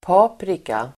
Uttal: [p'a:prika]